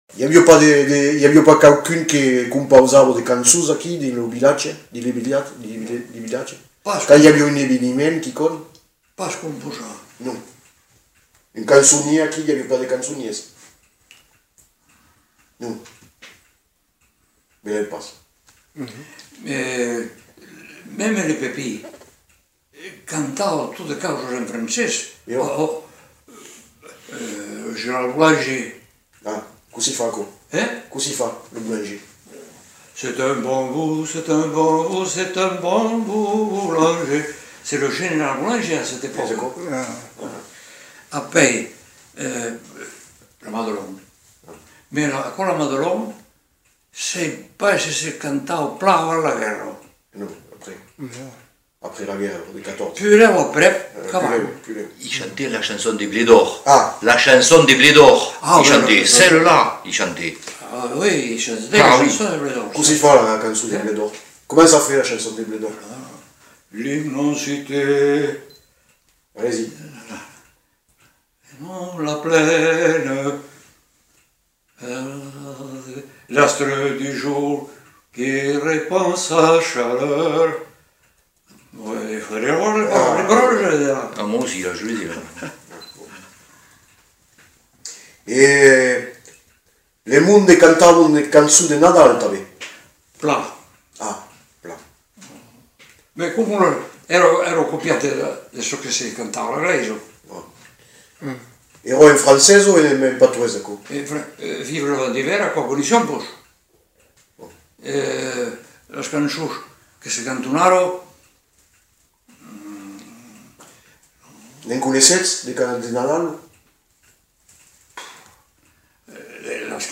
Aire culturelle : Lauragais
Lieu : Le Faget
Genre : témoignage thématique
Descripteurs : chant militaire
Notes consultables : L'informateur évoque plusieurs chants en français et en occitan, et en chante des bribes.